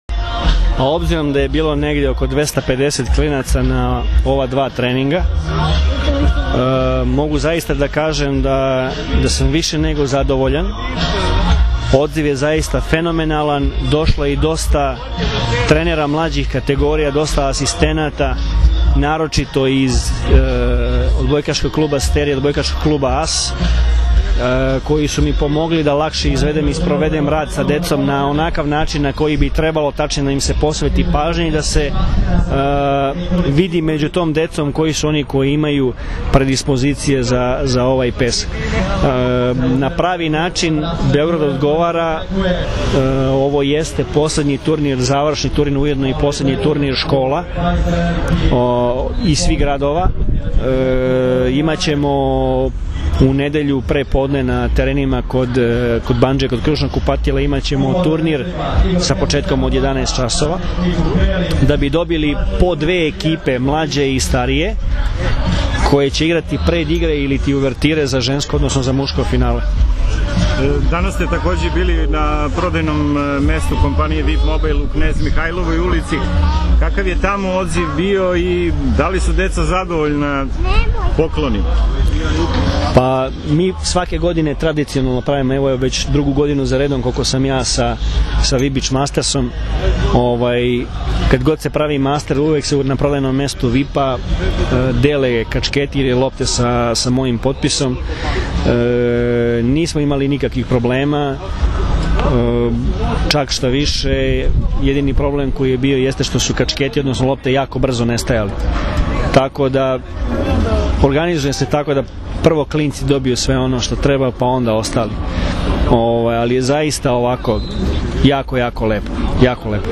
IZJAVA VLADIMIRA GRBIĆA